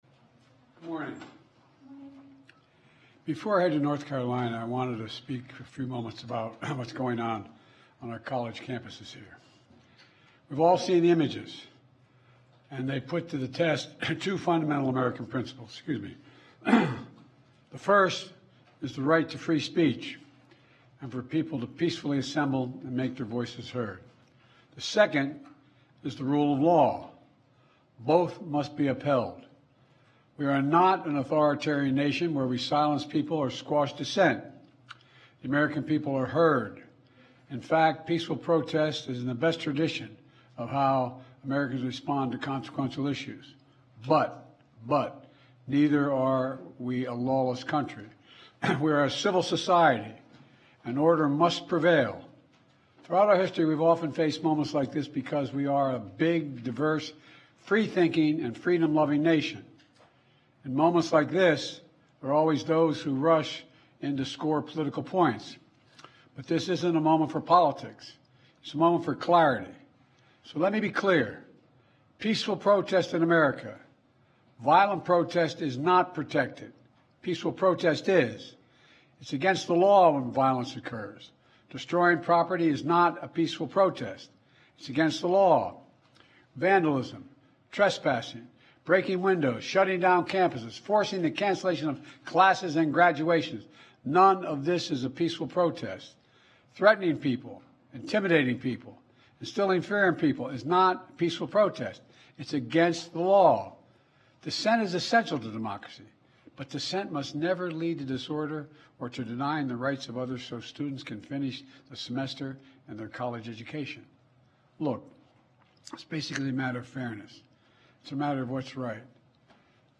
Joe Biden: Address on Recent College Campus Unrest (transcript-audio-video)
Remarks on Recent College Campus Unrest
delivered May 2 2024, White House, Washington, D.C.
Audio Note: AR-XE = American Rhetoric Extreme Enhancement